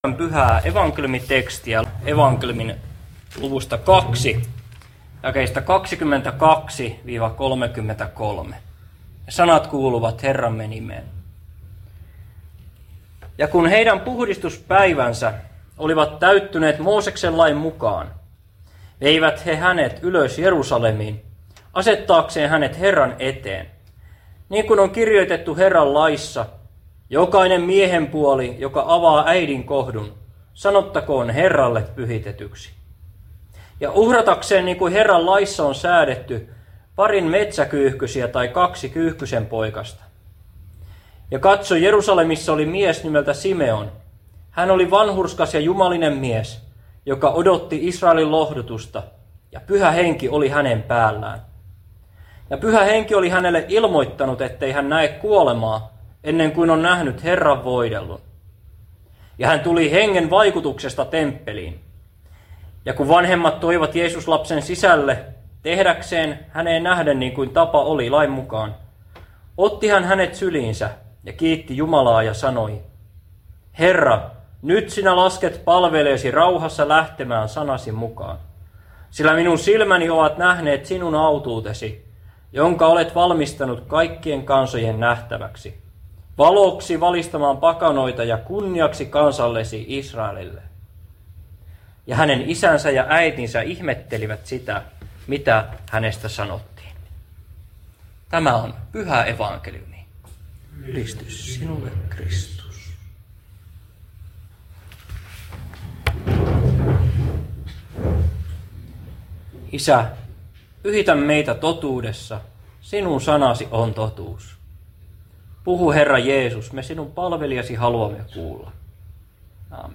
saarna Karstulassa kynttilänpäivänä